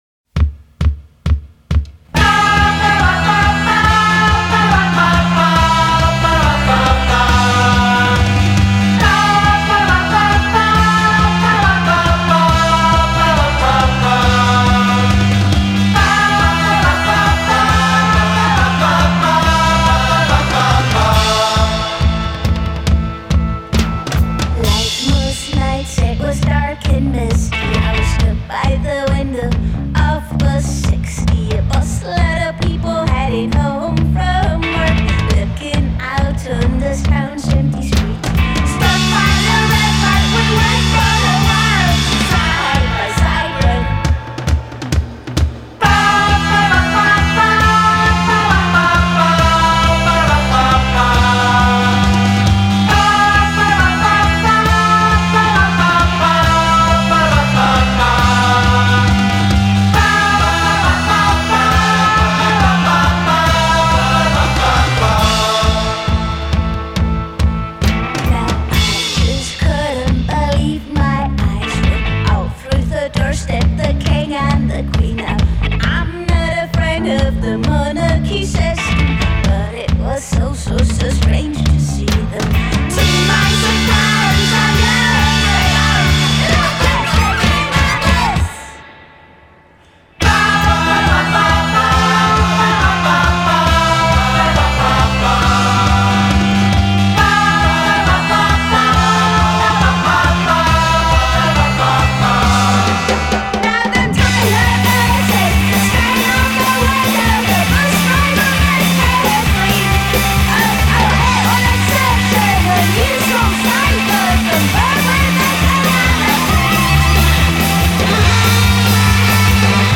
L'album, registrato dalla stessa band nel proprio studio